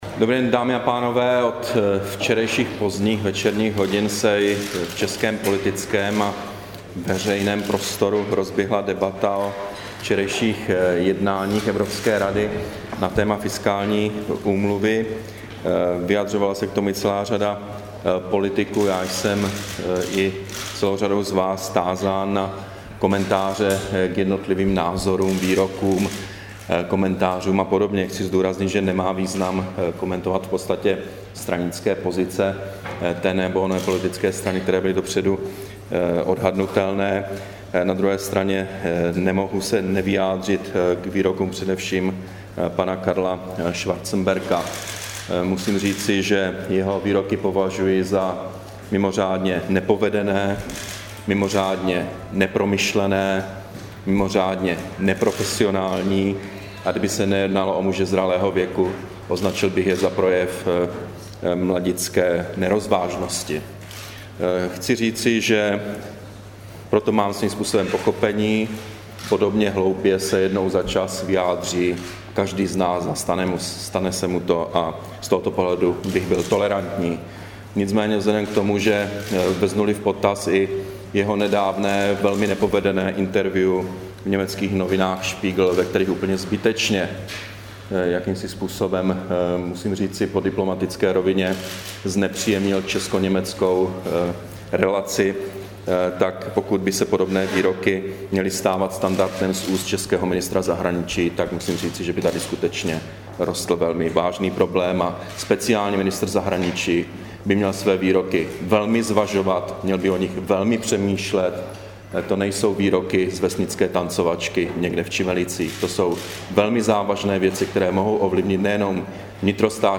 Brífink premiéra Petra Nečase k fiskální úmluvě, 31. ledna 2012